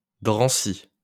wymowa) – miejscowość i gmina we Francji, w regionie Île-de-France, w departamencie Sekwana-Saint-Denis.